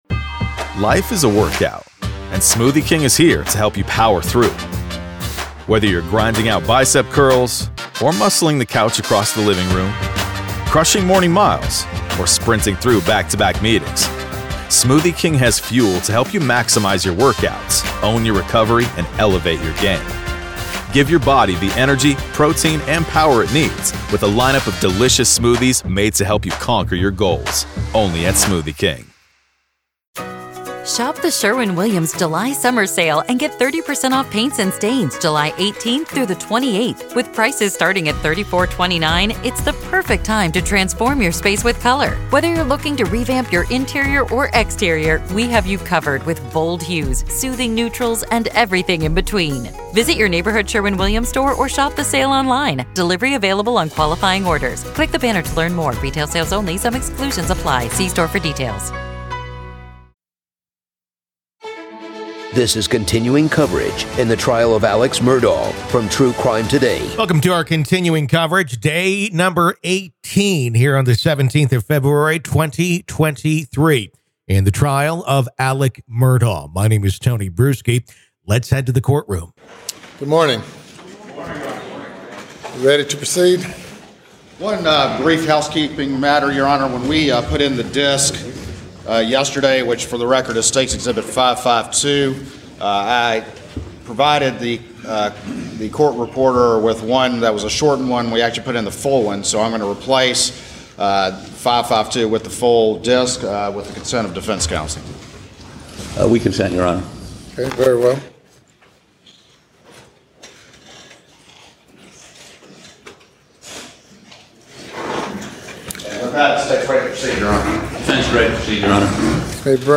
The Trial Of Alex Murdaugh | FULL TRIAL COVERAGE Day 18 - Part 1